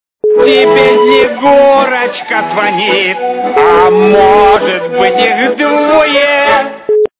» Звуки » Смешные » Тебе Снегурочка звонит.
При прослушивании Тебе Снегурочка звонит. - А может их и двое... качество понижено и присутствуют гудки.